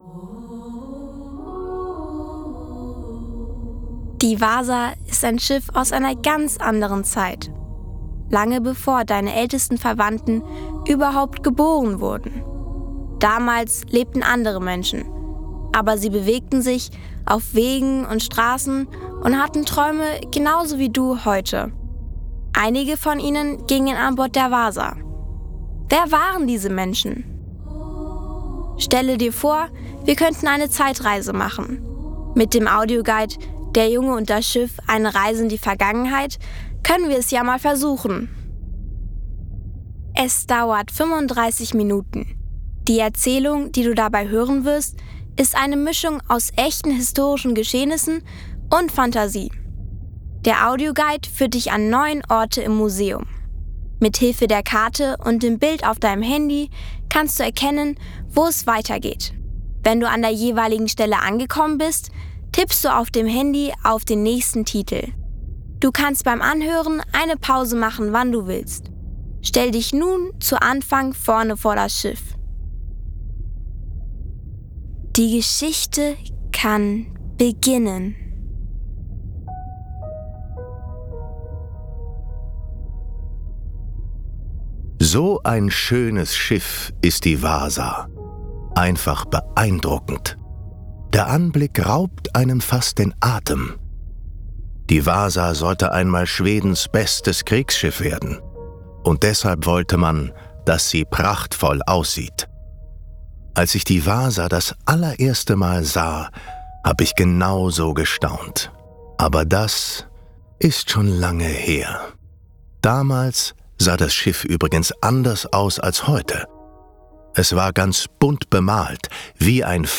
Audioguide für Kinder - deutsch